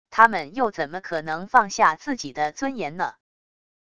他们又怎么可能放下自己的尊严呢wav音频生成系统WAV Audio Player